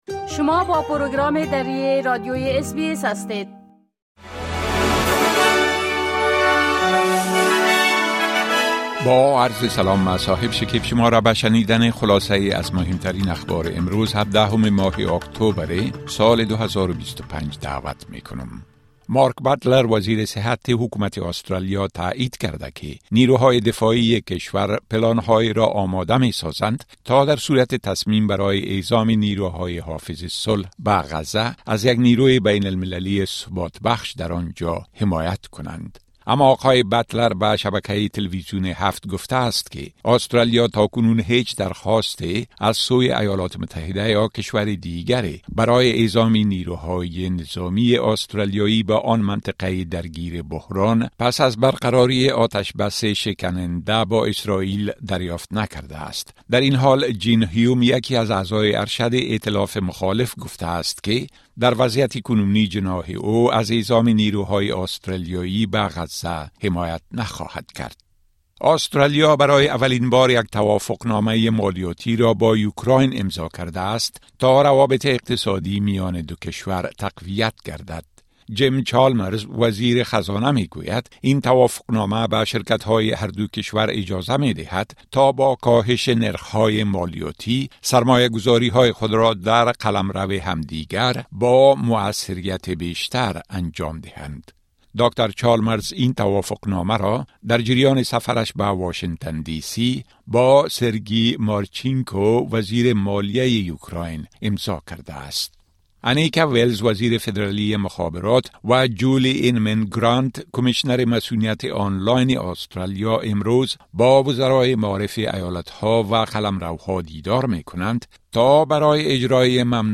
10 am News Update Source: SBS / SBS Filipino